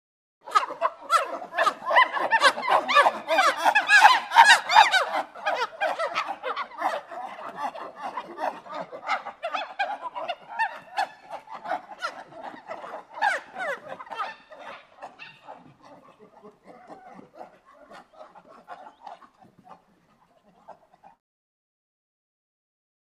Monkey ( Unknown ) Chatter. Group Of Monkeys Hoot And Scream. Close Perspective.